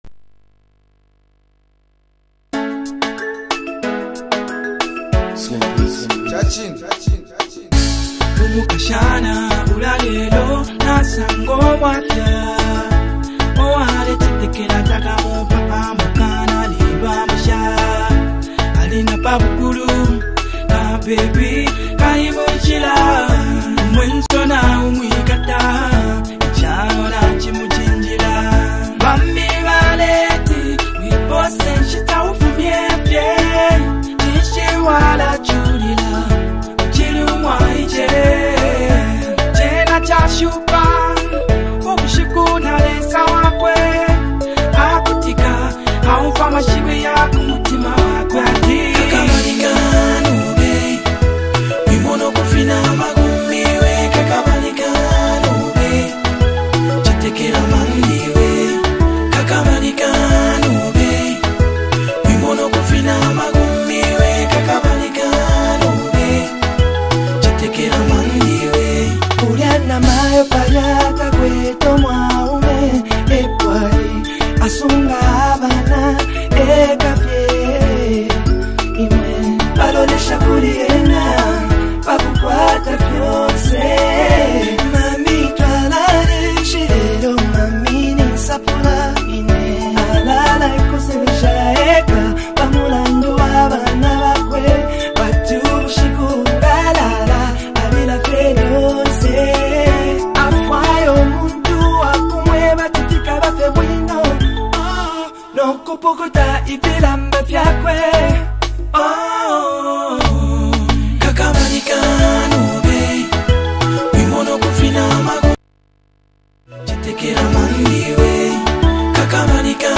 A Powerful Blend of Emotion and Afro-Soul
deeply emotional and melodically rich song
smooth vocals